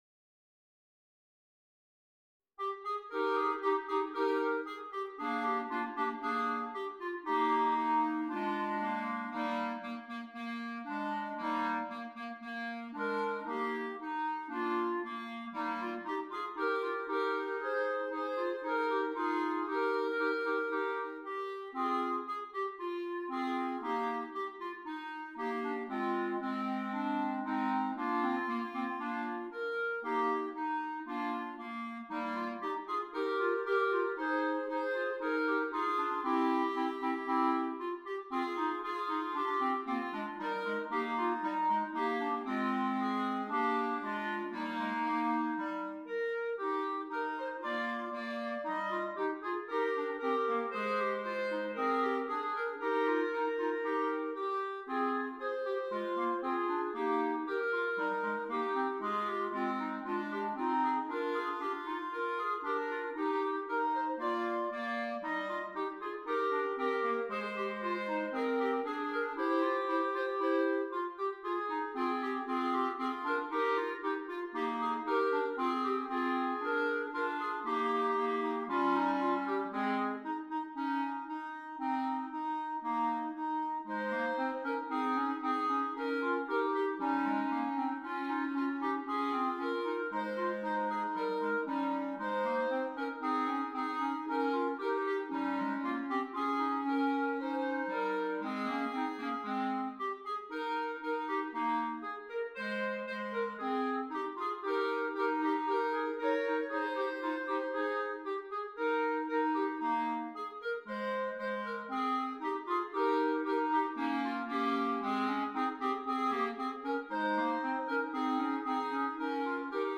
3 Clarinets